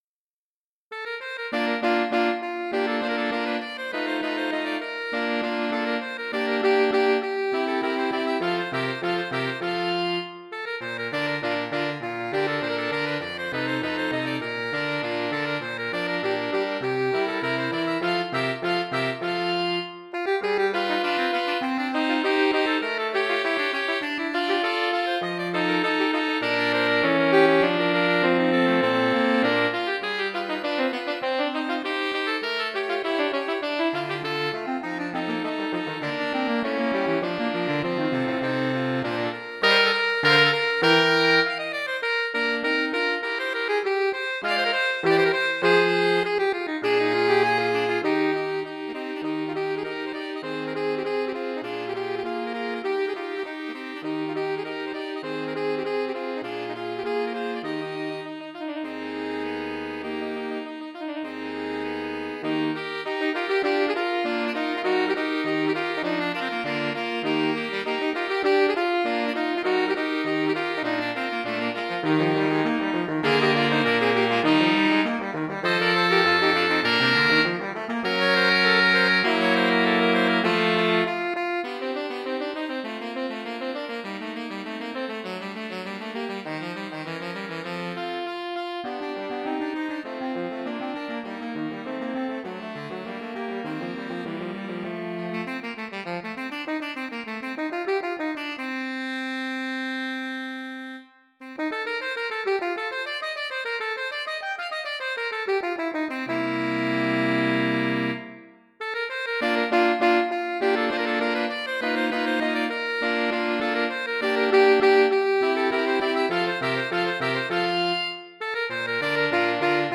Allegro. Bright and fun to play.